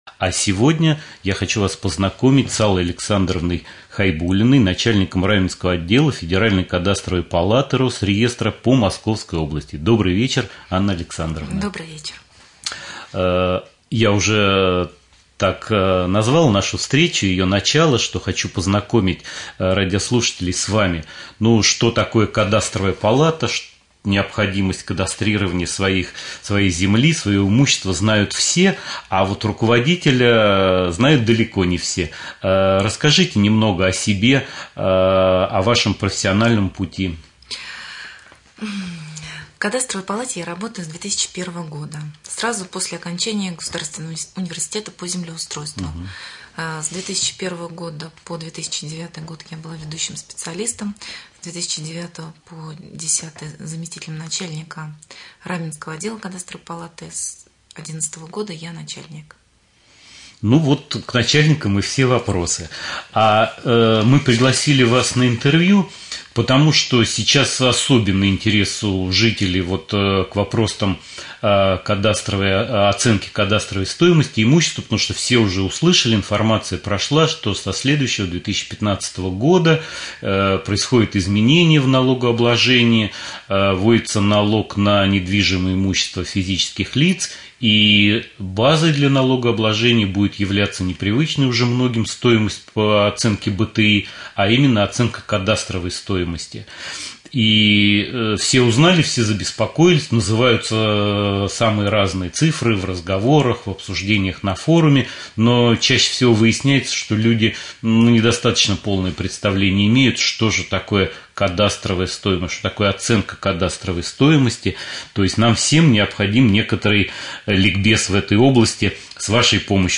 Прямой эфир.
1.Прямой-эфир.mp3